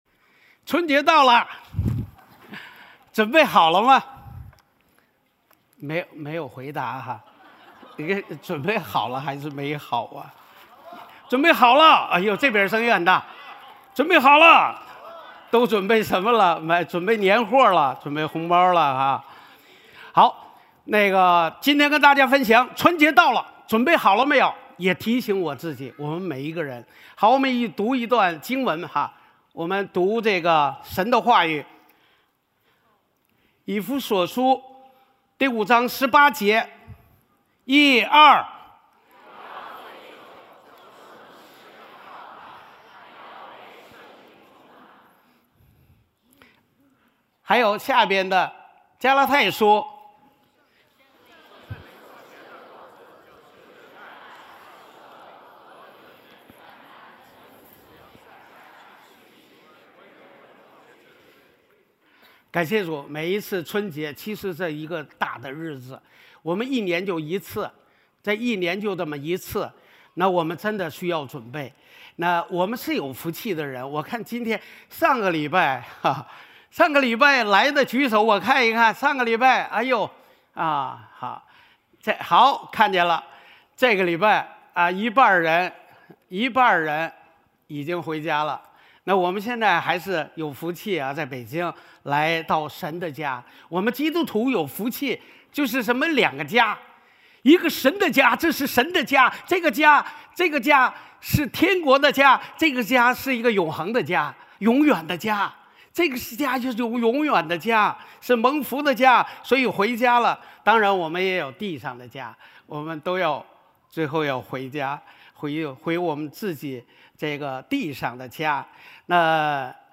主日证道｜春节到了，你准备好了吗？
Sermons